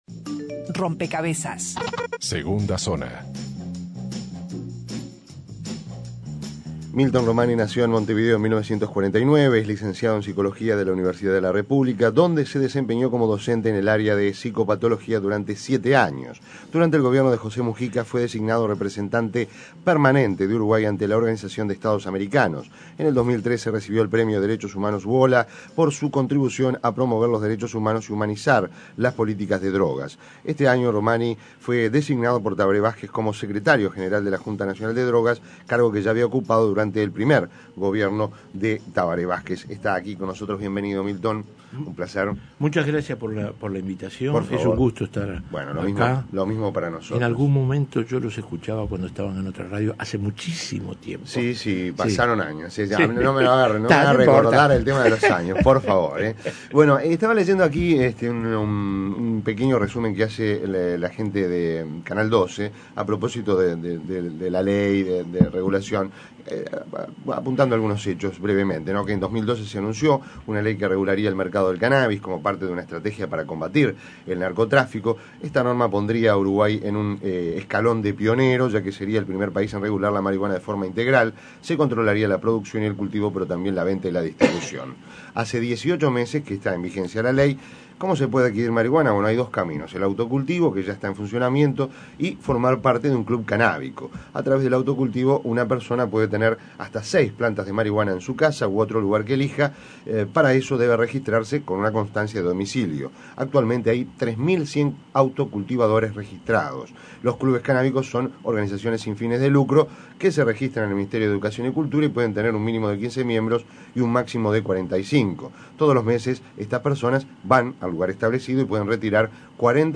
Descargar Audio no soportado Entrevista a Milton Romani Ver video completo